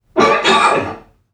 NPC_Creatures_Vocalisations_Robothead [37].wav